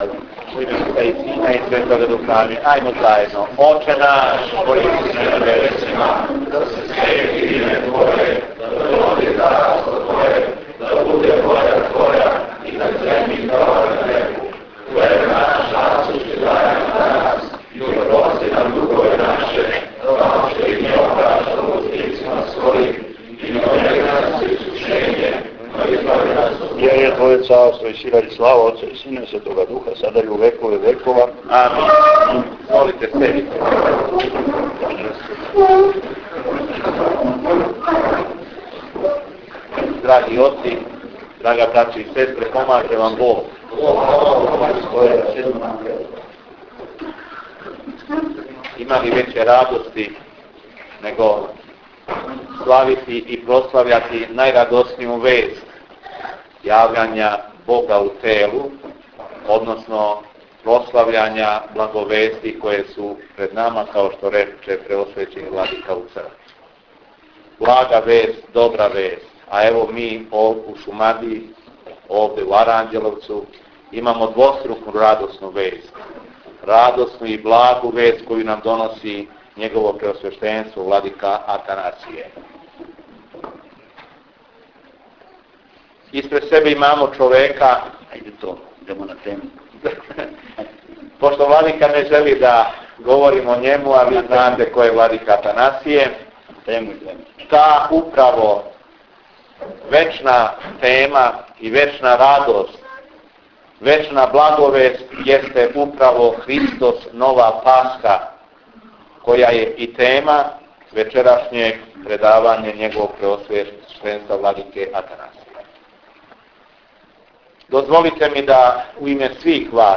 ПРЕДАВАЊЕ ВЛАДИКЕ АТАНАСИЈА У АРАНЂЕЛОВЦУ - Епархија Шумадијска
Предавање владике Атанасија у Аранђеловцу - 29 MB